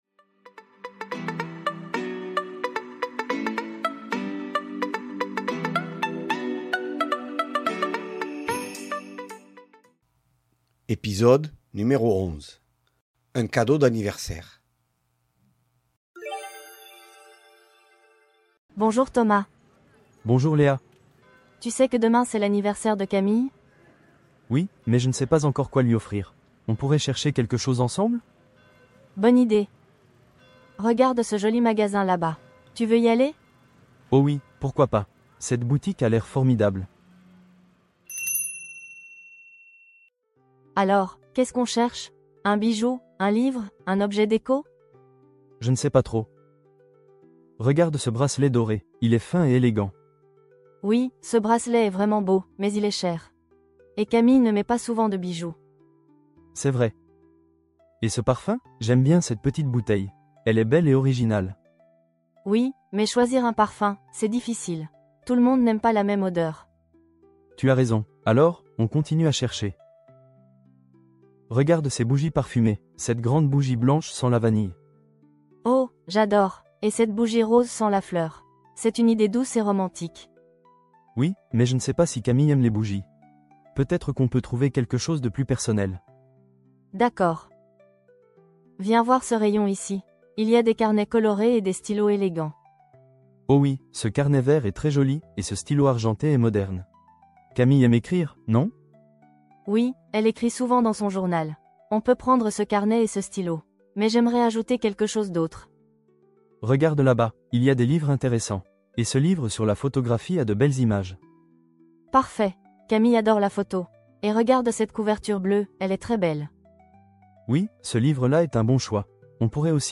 Voici un petit dialogue pour les débutants. Avec cet épisode, vous verrez la place des adjectifs et les adjectifds démonstratifs.